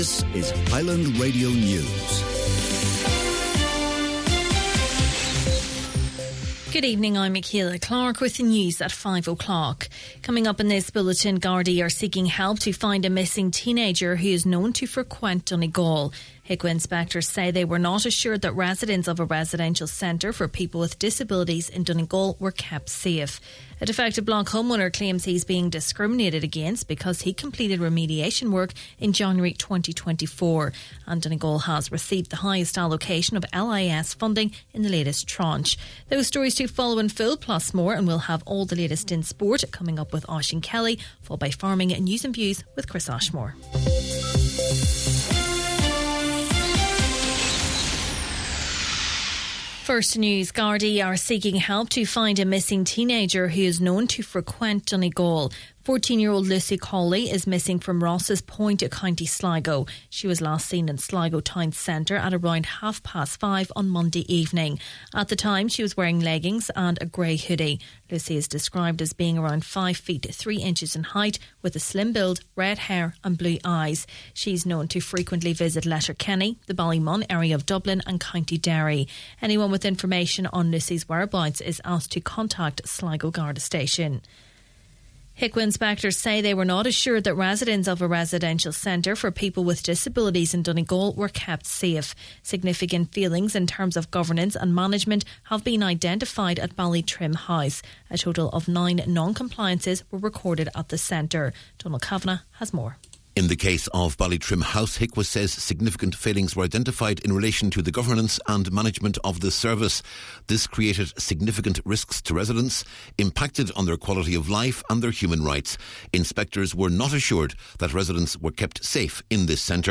Main Evening News, Sport, Farming News and Obituaries – Thursday, August 7th